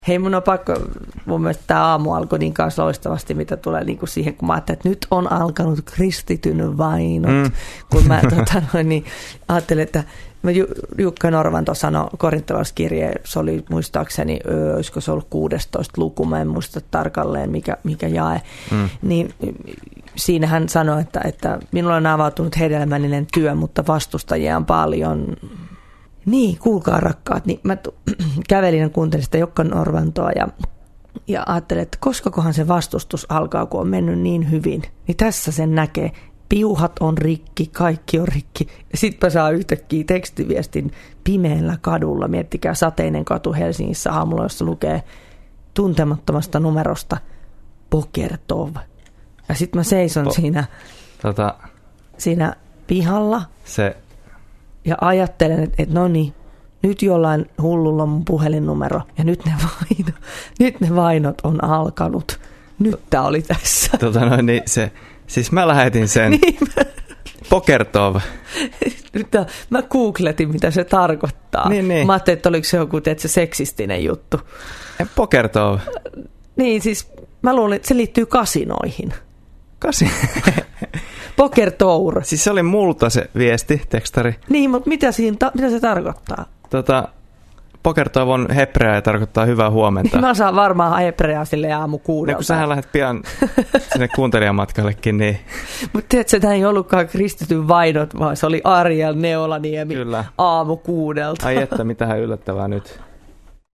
Radio Deissä aloitti maanantaina 4.12. uusi aamulähetys Herätys!